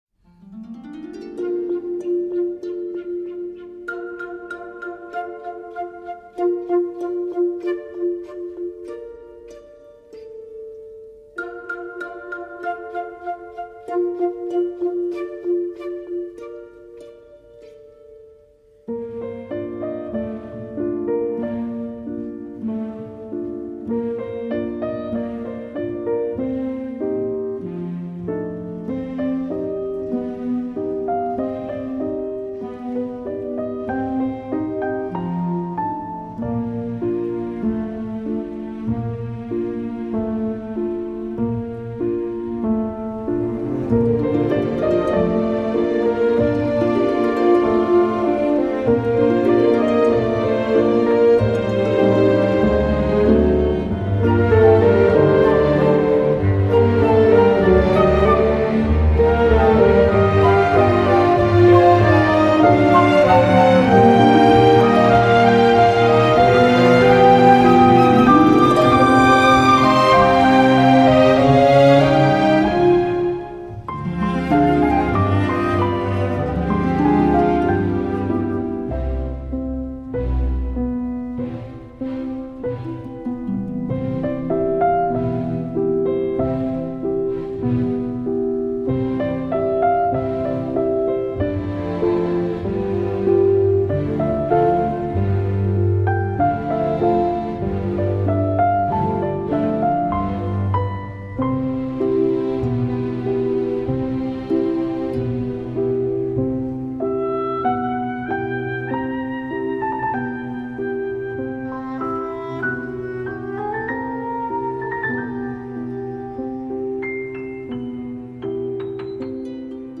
Mélodies simples, mémorables et évocatrices.